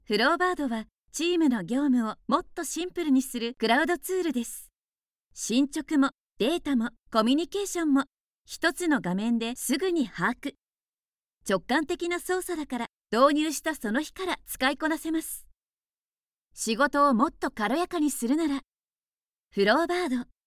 中低音～低音の響く声が特徴です。透明感のあるナレーションや、倍音を活かした語りを得意としています。
– ナレーション –
明るい、爽やかな